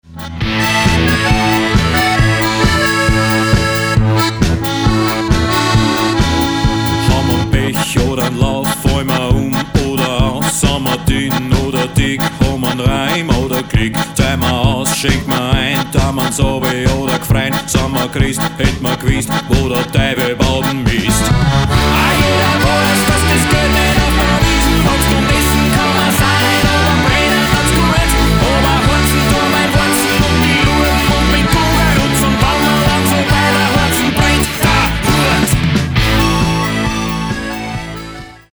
Gesang
Gesang & Schlagzeug
Gitarre
Keyboards
E-Bass